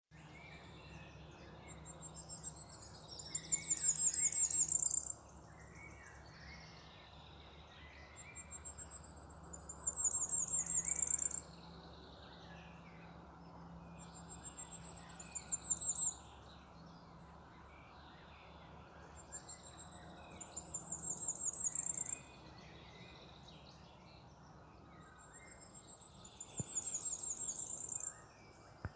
Firecrest, Regulus ignicapilla
StatusSinging male in breeding season